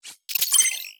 Hi Tech Alert 15.wav